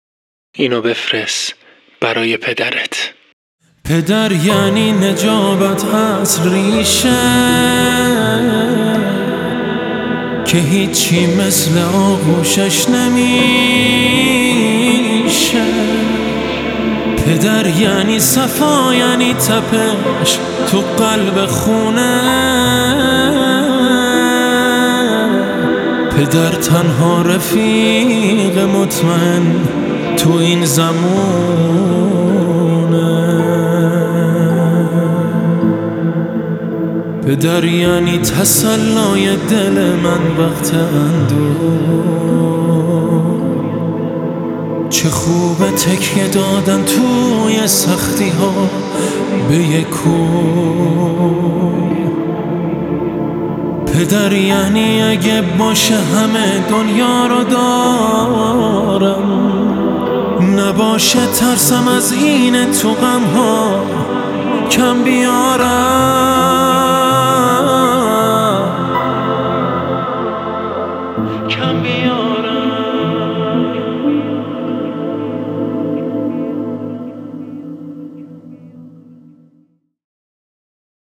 تک آهنگ
موسیقی پاپ